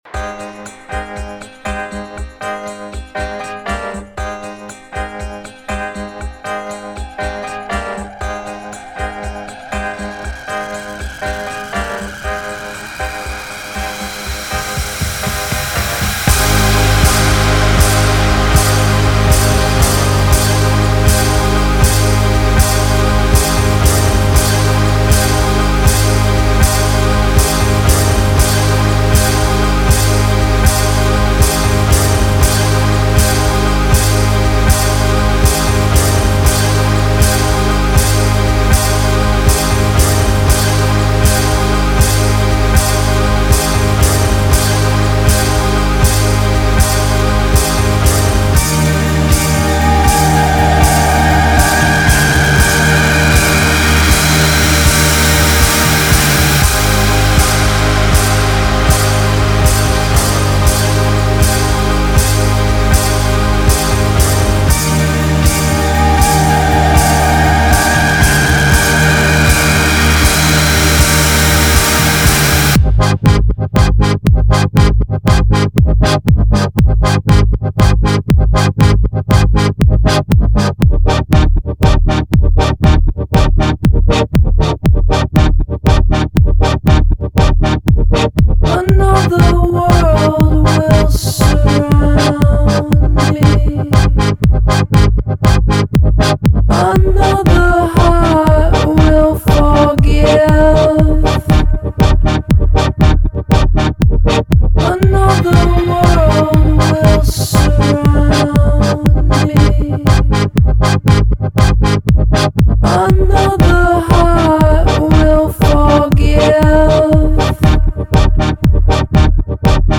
I think it’s just way too repetitive.
I REALLY like this.
Your right when you say this beat is incomparable.